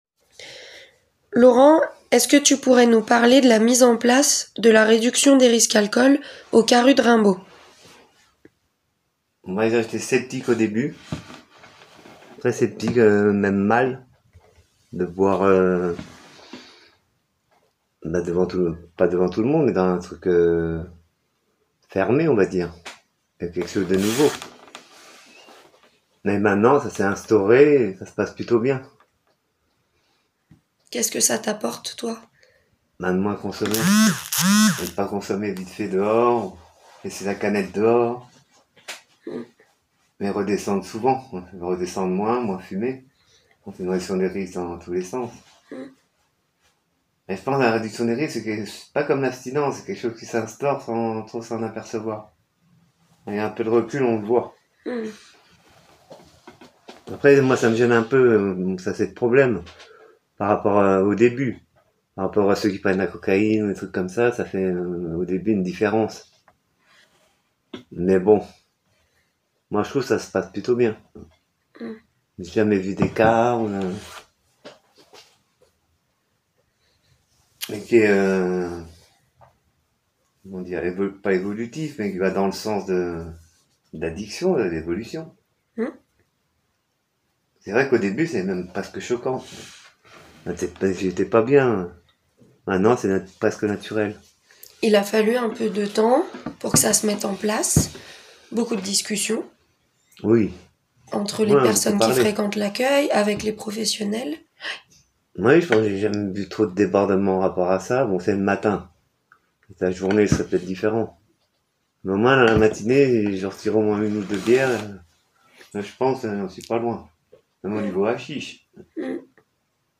Les personnes concernées parlent du CAARUD Trois personnes accueillies ont témoigné autour de l’accompagnement proposé au CAARUD de Saint Etienne : Le premier nous explique ce qu’il vient chercher au CAARUD, le second ce qu’est le CAARUD pour lui et le troisième évoque la réduction des risques et des dommages alcool que nous avons mise en place en 2024. Interview